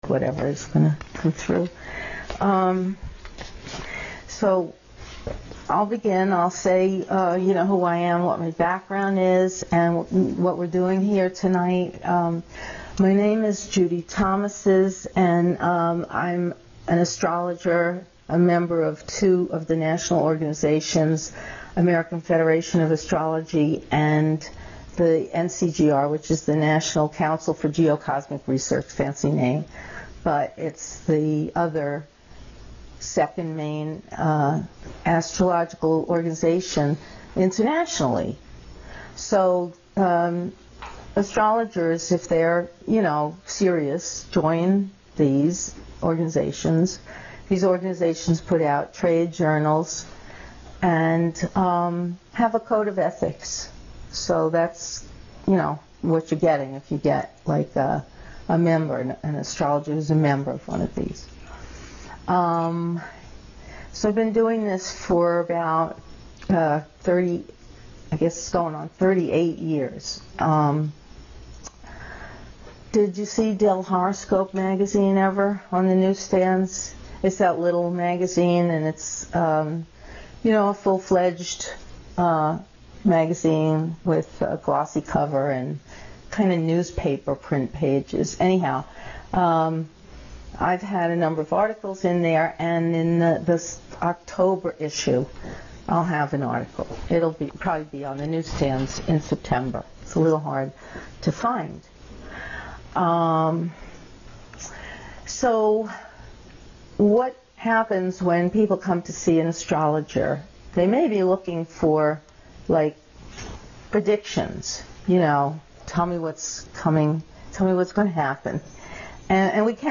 _AstrologyKarmaTalkBellBookCandle7-18-12.MP3